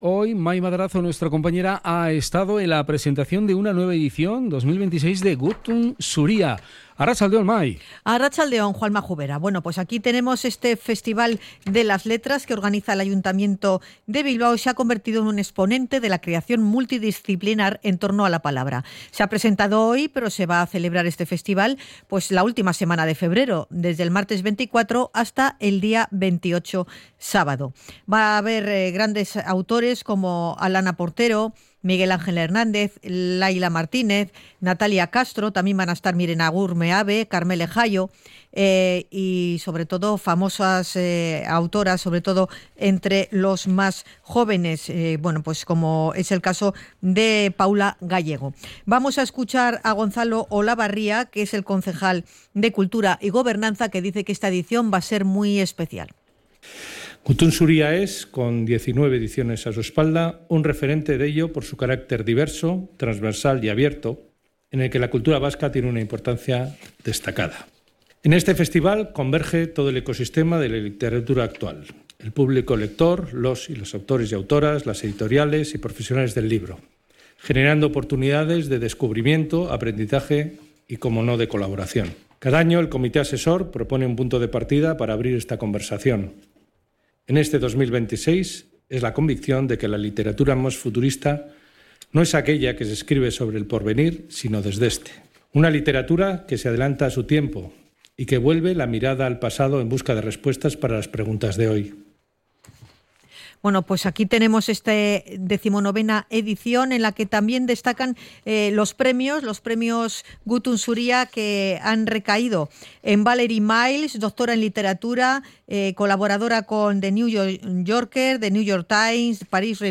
Hemos estado en la presentación del Festival Gutun Zuria Bilbao